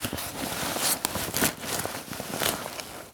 foley_sports_bag_movements_11.wav